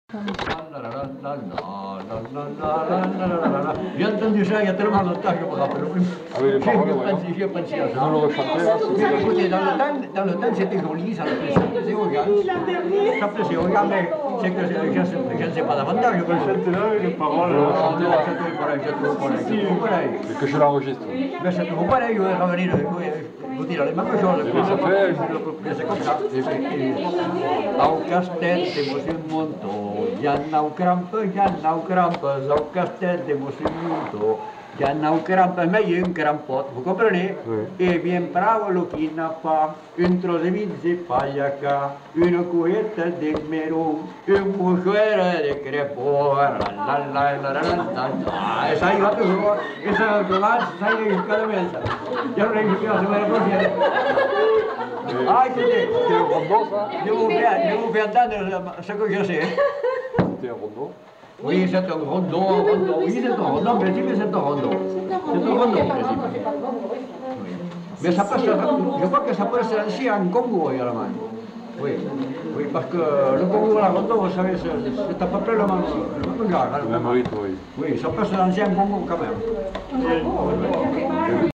Lieu : Allons
Genre : chant
Effectif : 1
Type de voix : voix d'homme
Production du son : chanté ; fredonné
Danse : congo